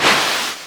watr_out.ogg